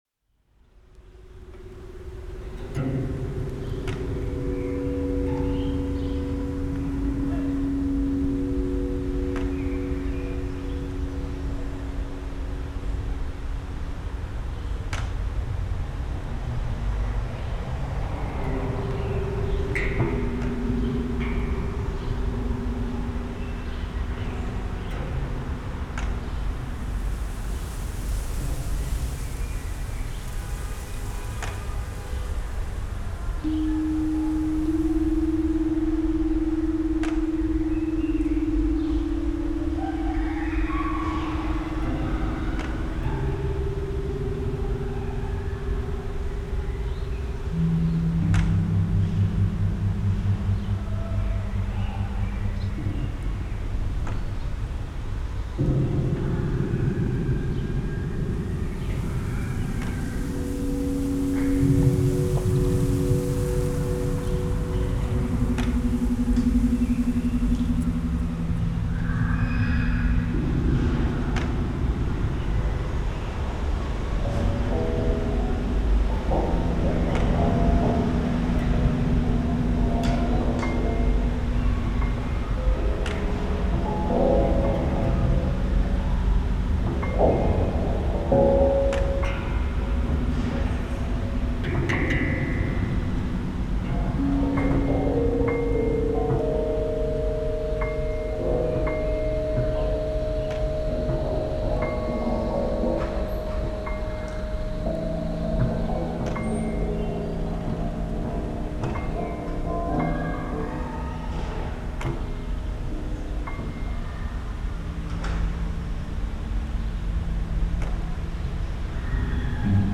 live performance
lockdown 2 ist ein live konzert unter pandemischen bedingungen im schaufenster der clb gallerie. interessierte und vorbeigehende können sich mit hilfe eines qr codes einloggen und live hören.
komposition für elektrischen bass, live fx und umgebungsklang vor ort.
elektrischer bass, live fx